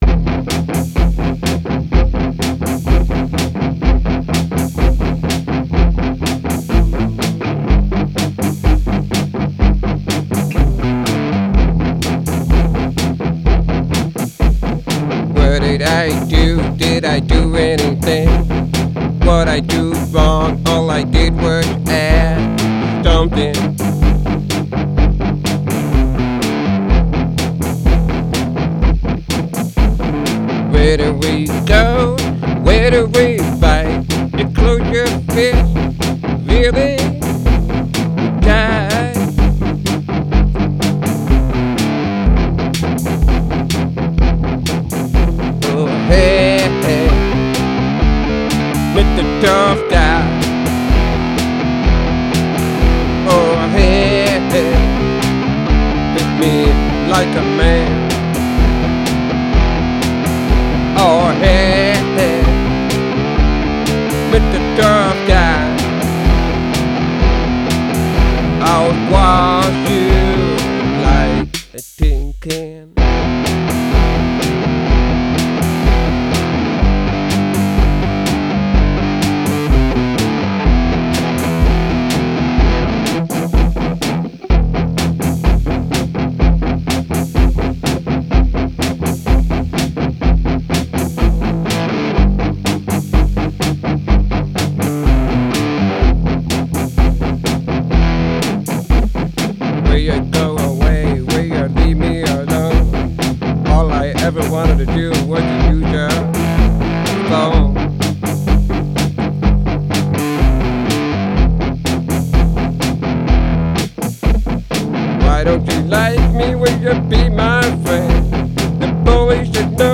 Here’s the full band rock version. It’s heavy rock & blues. It’s still not perfect, I still need to fix it up some, but I love how it came out.
I do know the screaming and growling toward the end of the song, needs a little more practice, but that’s the way I always sung the song at the live shows as a way to give the song an energetic feel.